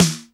HR16B SNR 08.wav